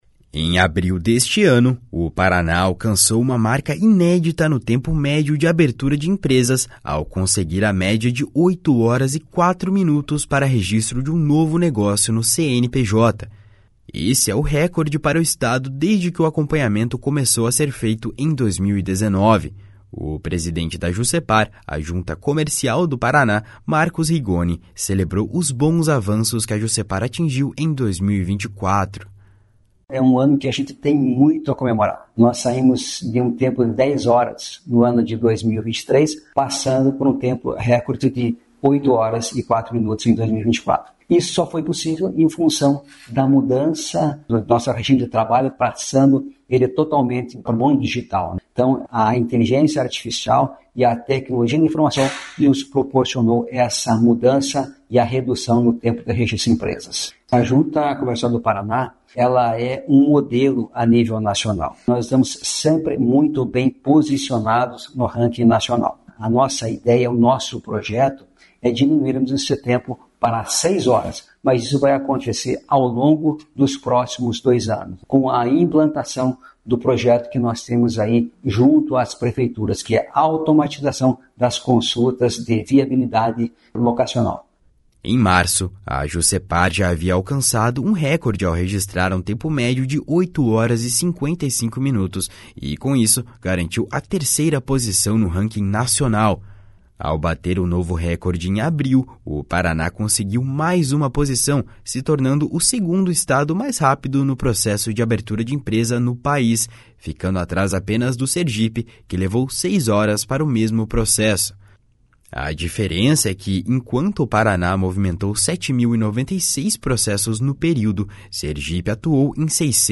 O presidente da Junta Comercial do Paraná, Marcos Rigoni, celebrou os bons avanços que a Jucepar atingiu em 2024. // SONORA MARCOS RIGONI //